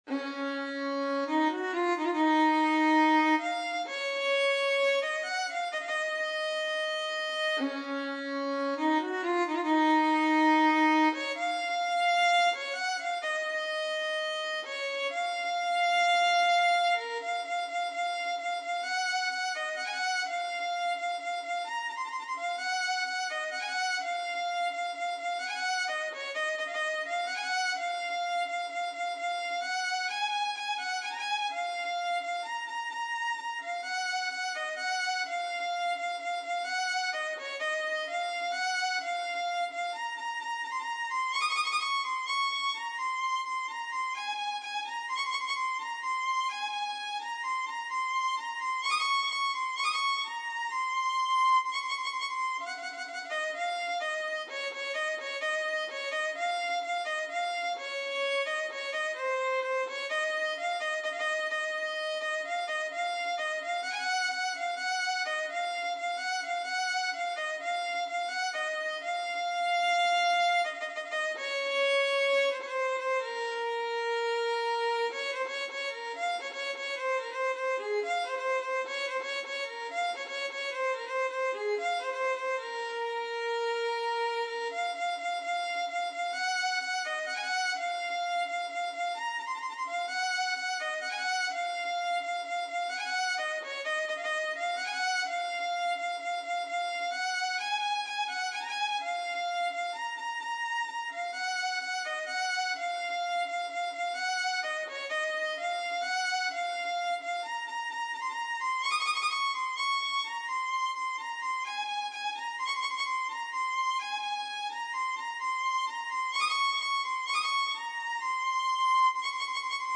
سطح : متوسط
ویولون